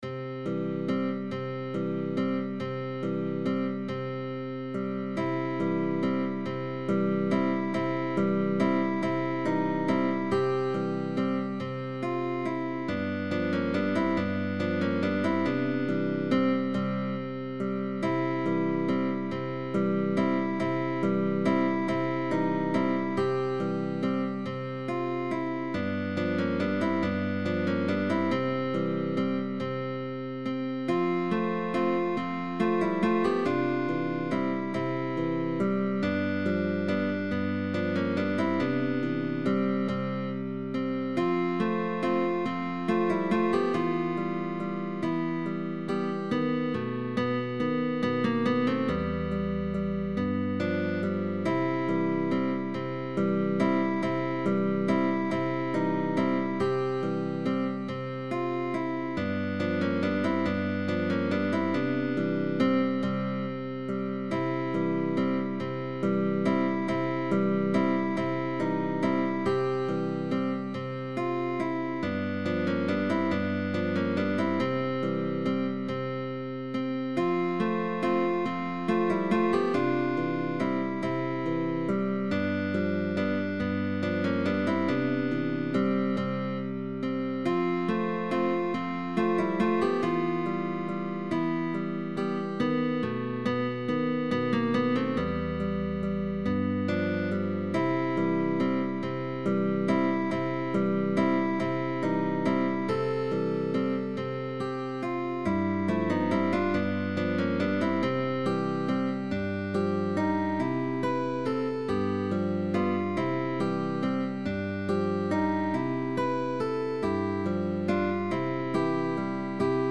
Guitar trio sheetmusic.
GUITAR TRIO